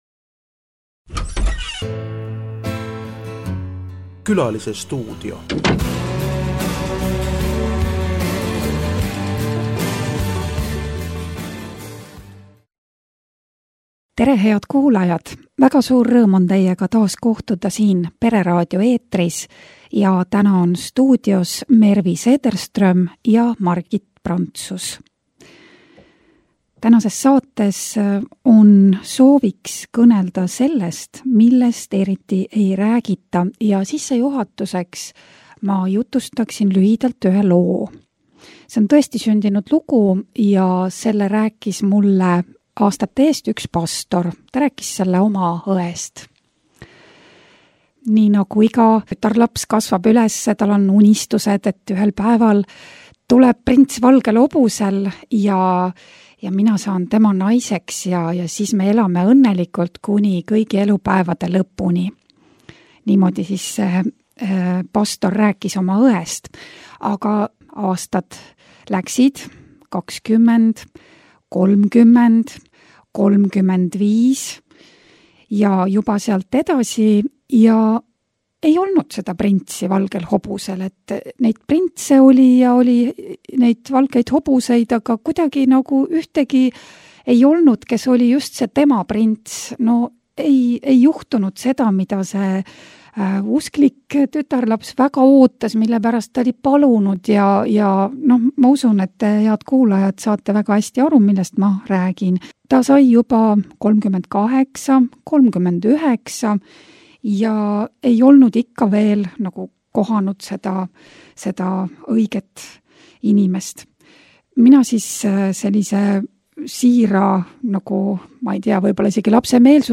Pereraadio saade oli eetris 22.10.2025.